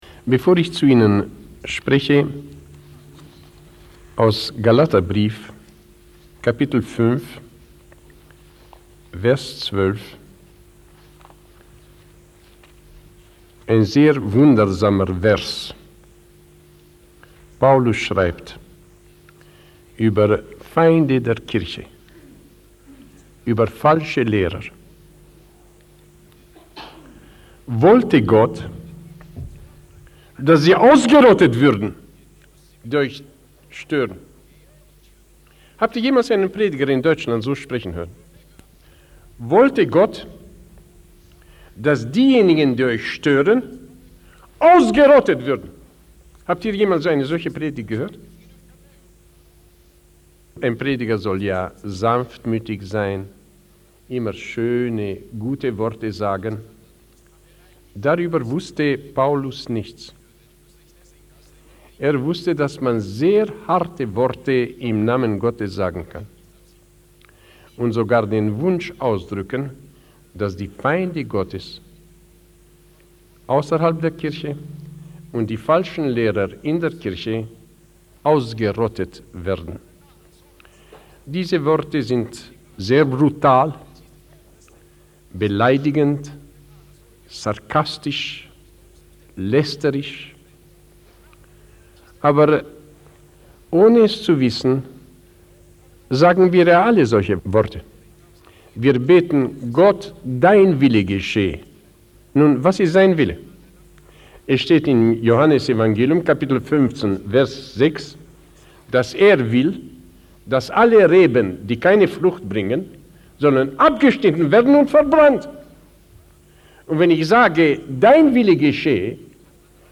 Die Feinde Des Evangeliums Sollen Abgeschnitten Werden (German) by Richard Wurmbrand | SermonIndex